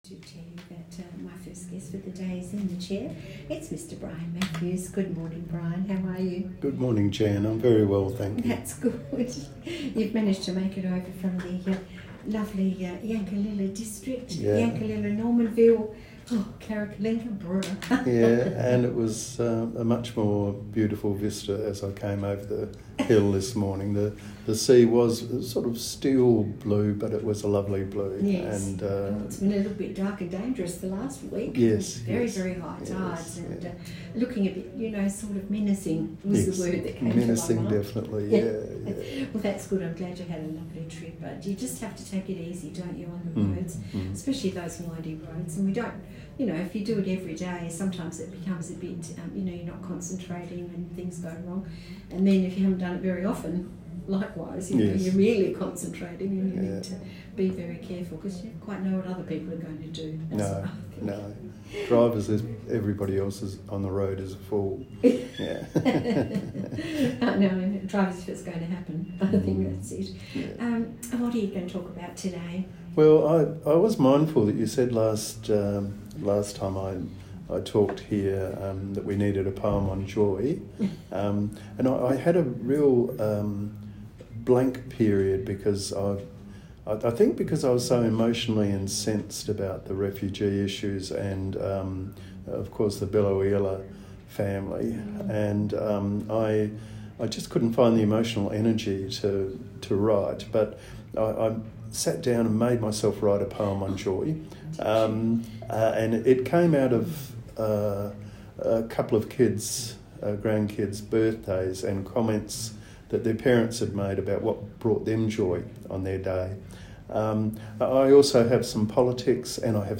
Some poetry I did in a poetry reading recently